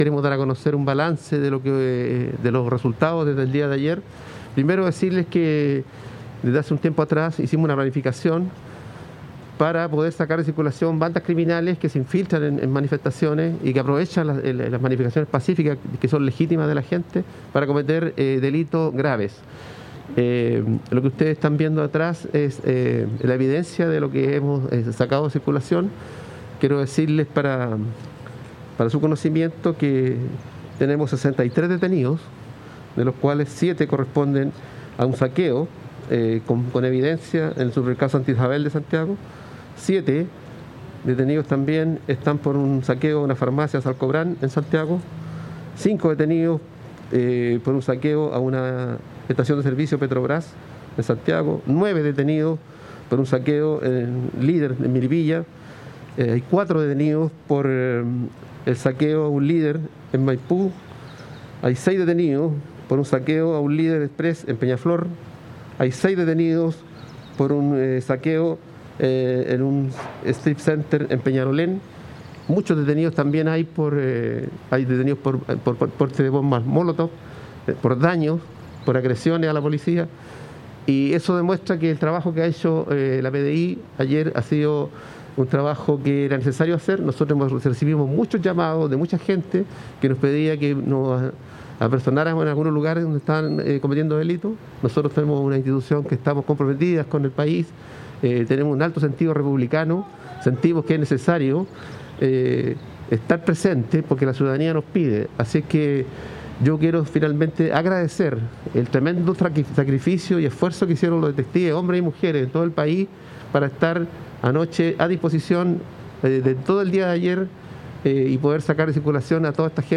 Así lo indicó el Director General de la PDI, Héctor Espinosa, quien destacó la labor de los efectivos locales durante un punto de prensa efectuado en la mañana.
Director-General-PDI.mp3